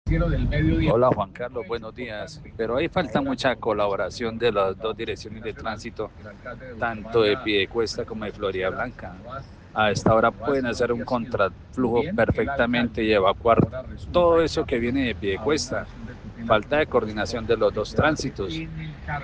Oyentes reportaron trancón en autopista de Bucaramanga